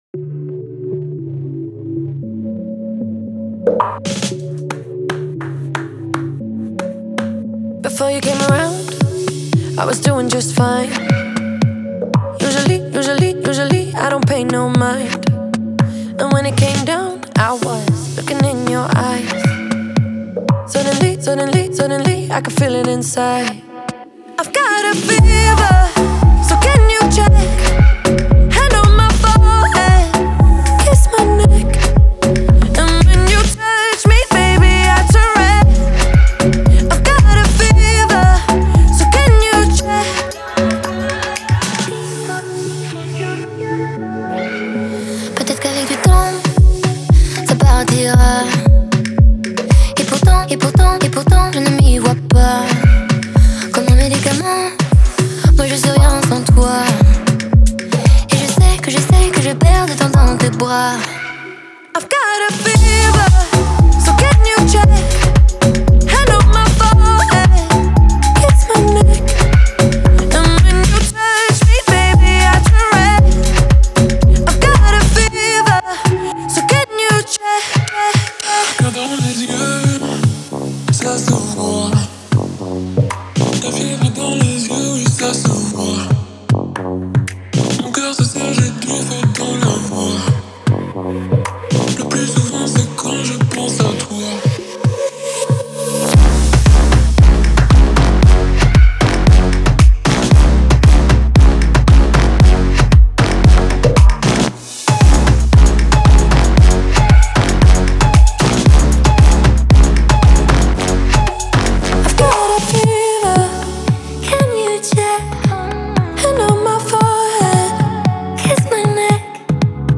это яркий и энергичный трек в жанре поп и EDM